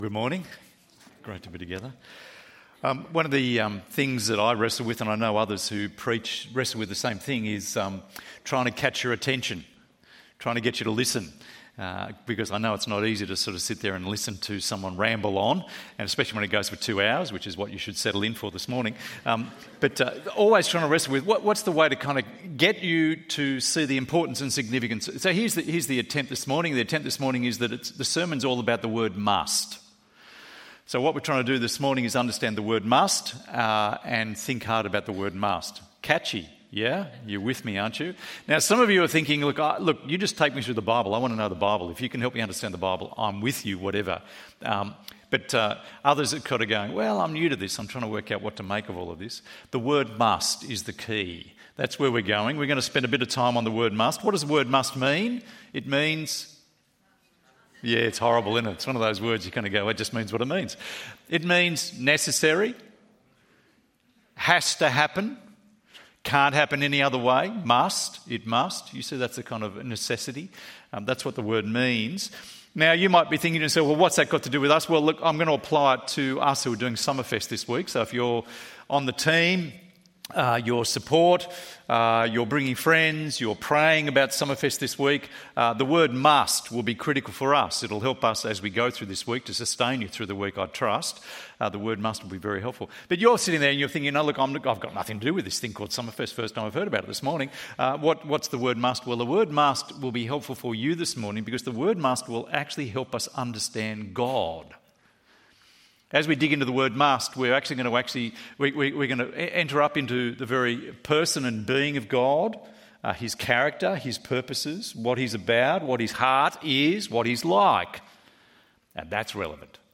God loves us and wants to save ~ EV Church Sermons Podcast